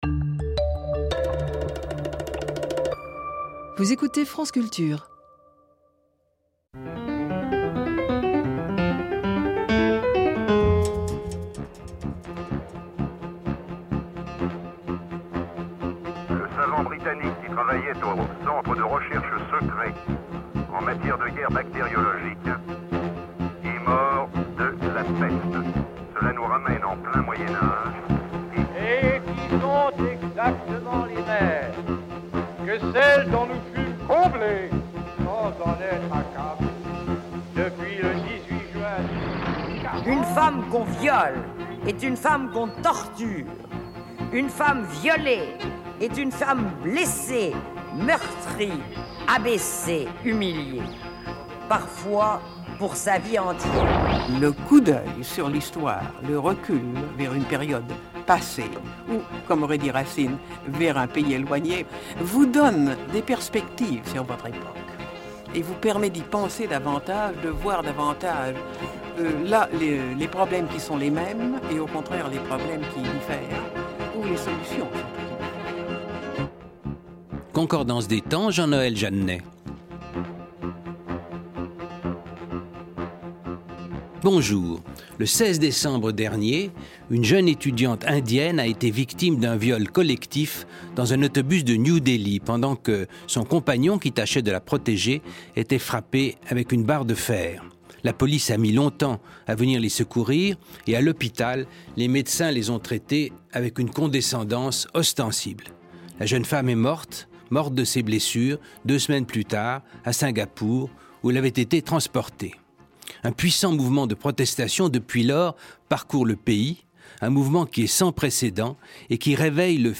Georges Vigarello, directeur de recherche à l’EHESS.